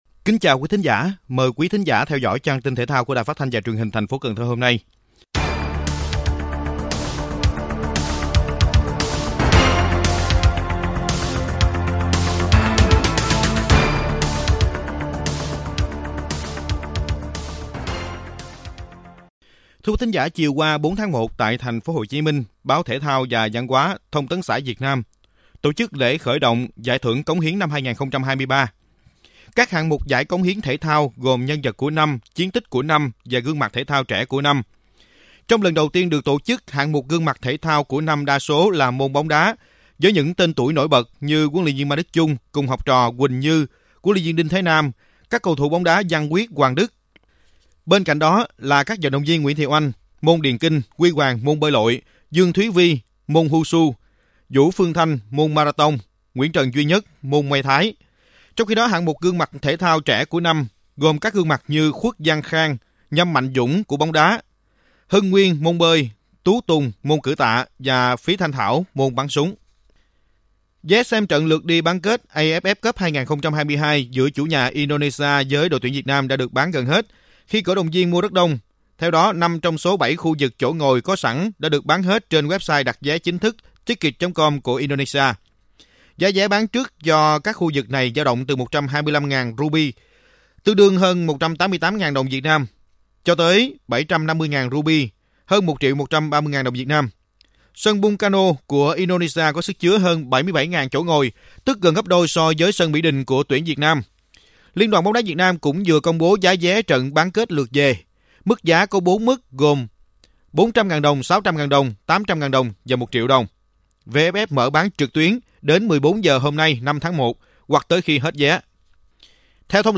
RadioThể thao
Bản tin thể thao 5/1/2023